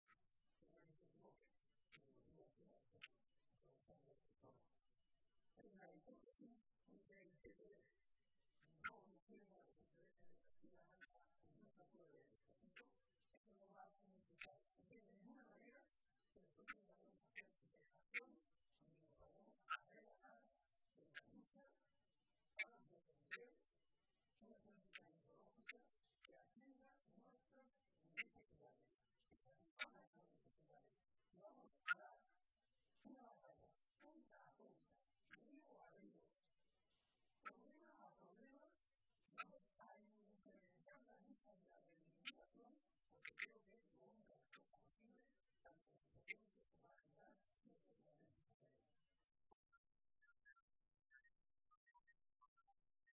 Momento de la clausura del Foro Joven de JSCM